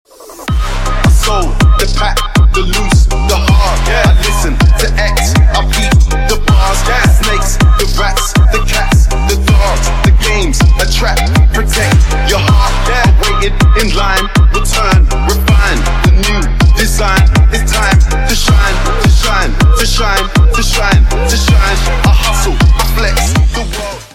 Trap рингтоны